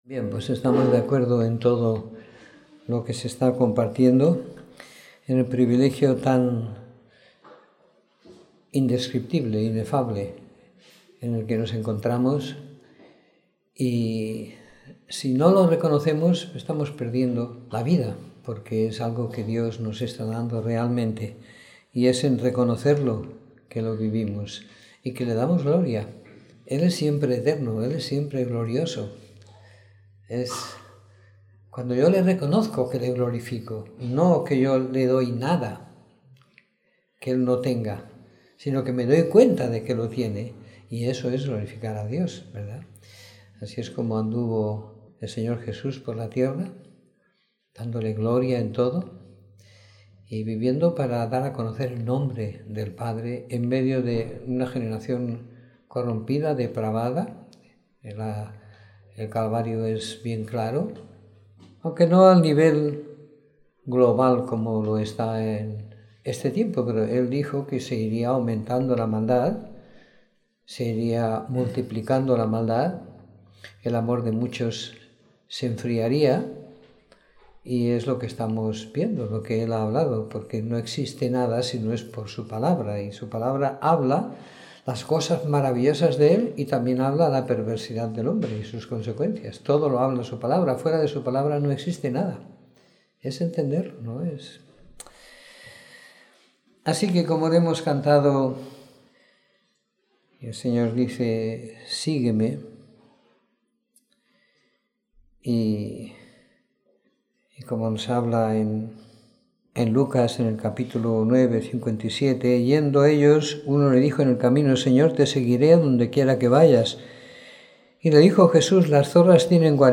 Domingo por la Tarde . 05 de Junio de 2016